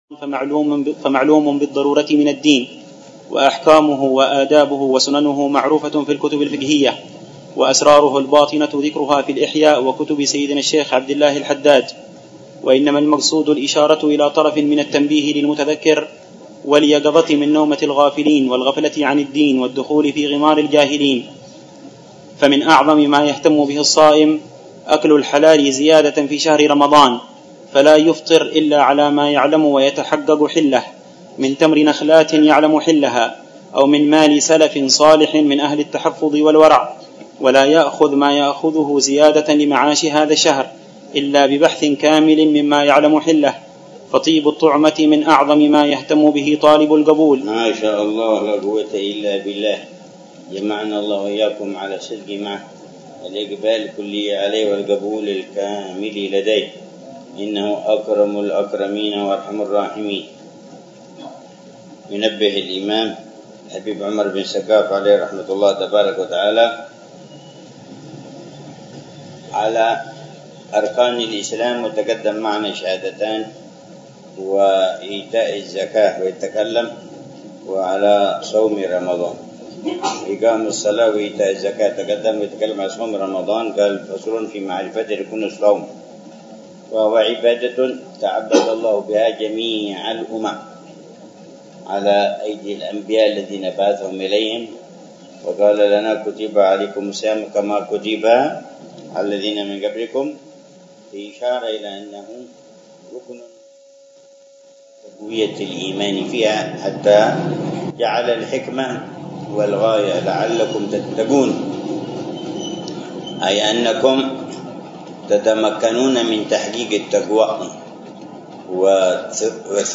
الدرس الثاني عشر من شرح العلامة الحبيب عمر بن محمد بن حفيظ لكتاب : تنبيه الغافل وإرشاد الجاهل للإمام الحبيب : عمر بن سقاف بن محمد الصافي السقا